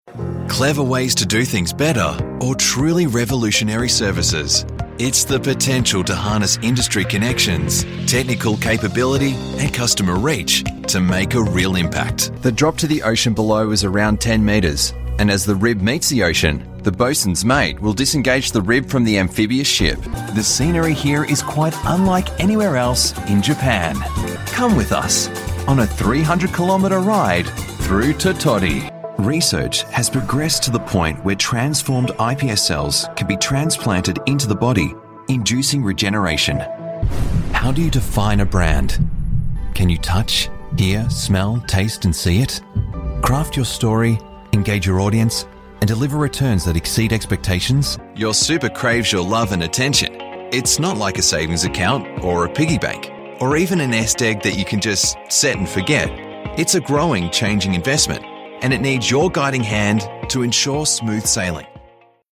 Male
Variety Of Corporate Reads
Words that describe my voice are Australian Accent, Versatile, Professional.